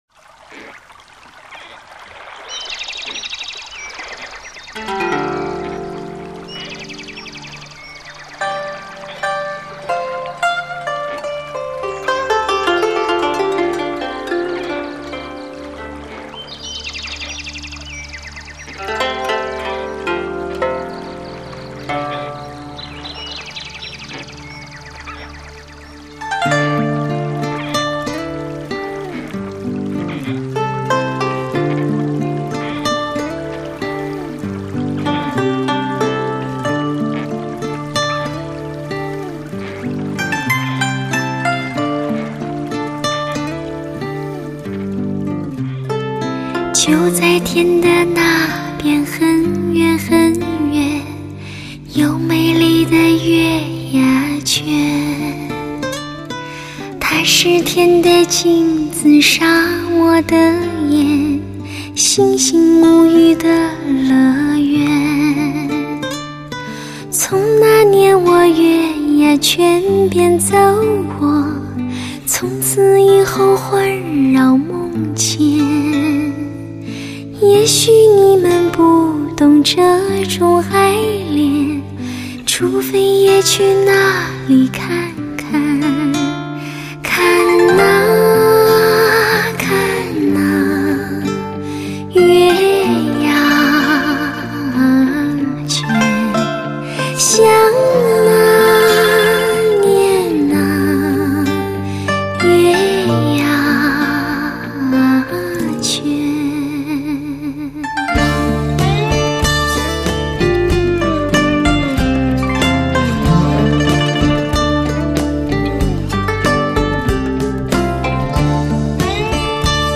类型: 汽车音乐
人声演绎自然极致，款款情深直达心灵；只有聆听才能体会，柔美嗓音，动人心魄……